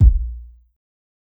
Kicks
KICK_MILEY.wav